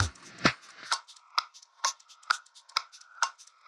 Index of /musicradar/uk-garage-samples/130bpm Lines n Loops/Beats
GA_BeatErevrev130-03.wav